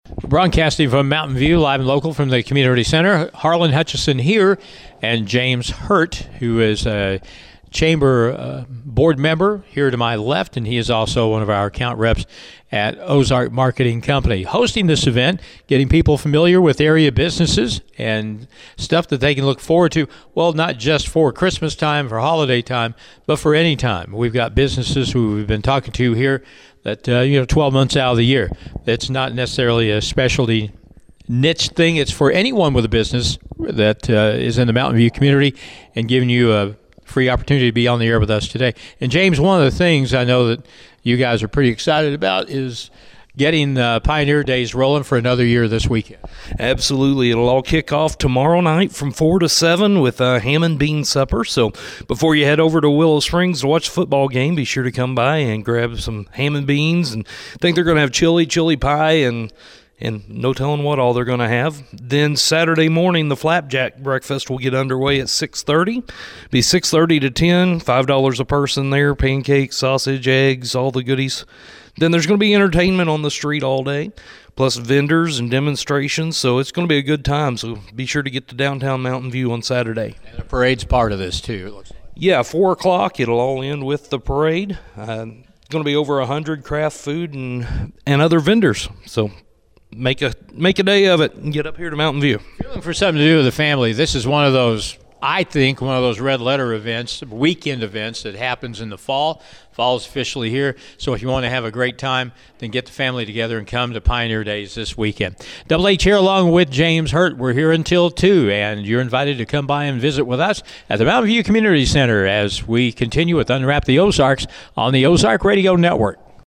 Ozark Marketing with the support of local businesses set up an interview station at the Mountain View Community Center and interviewed area business owners.